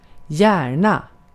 Ääntäminen
US : IPA : [suːn]